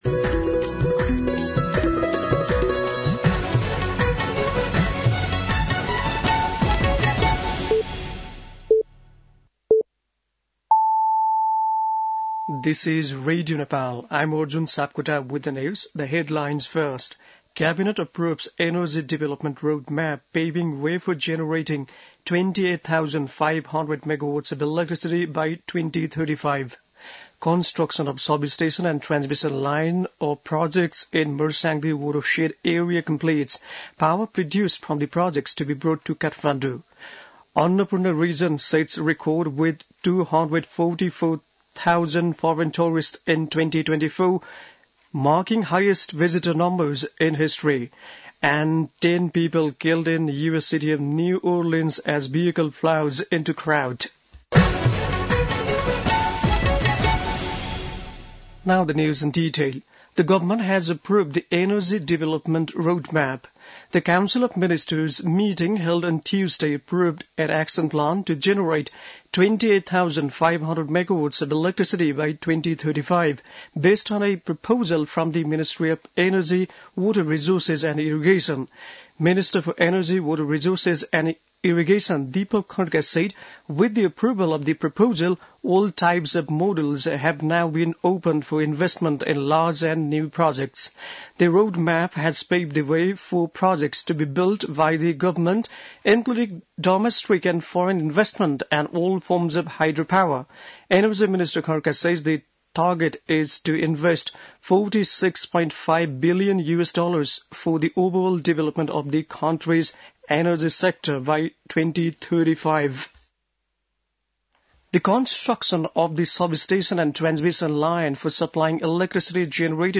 बेलुकी ८ बजेको अङ्ग्रेजी समाचार : १८ पुष , २०८१